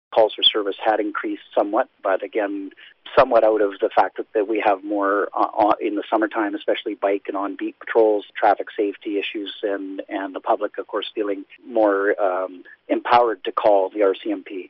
The mayor of Duncan says more people are making 911 calls in the area.